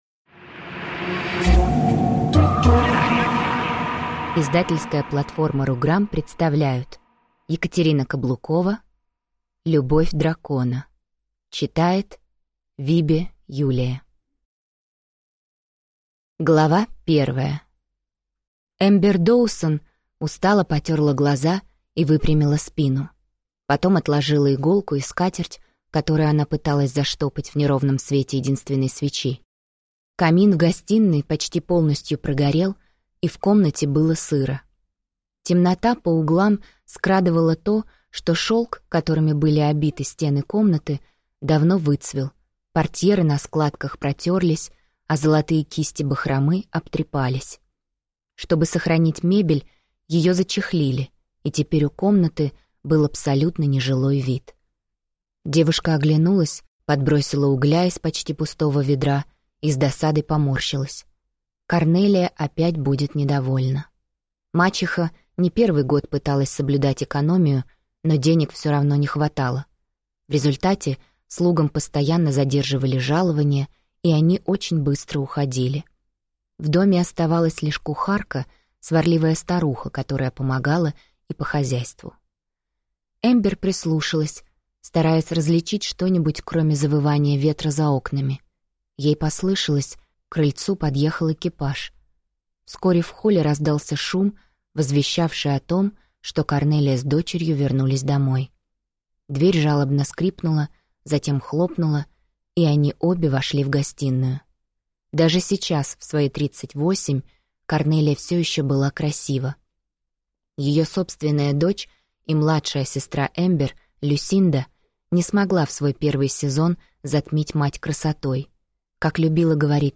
Аудиокнига Любовь дракона | Библиотека аудиокниг